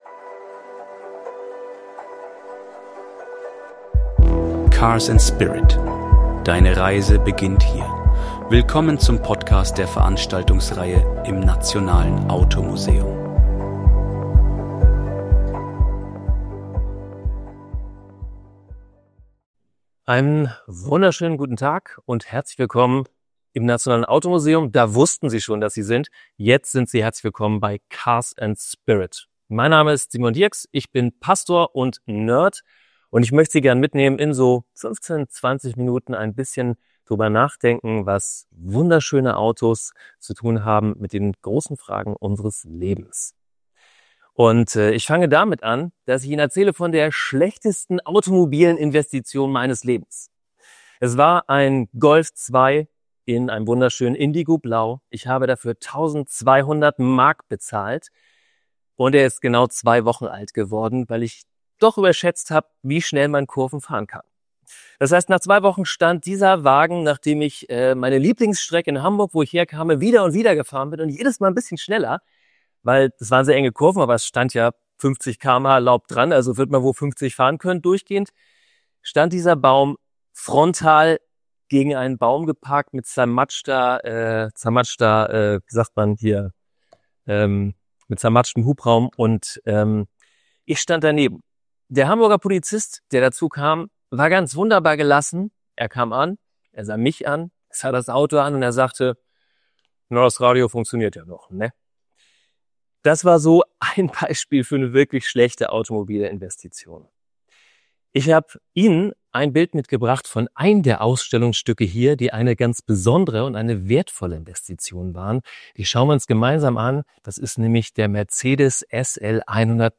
Die Veranstaltungsreihe im Nationalen Automuseum.
Vortrag im Nationalen Automuseum